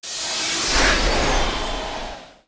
PowerUpFinal_2.wav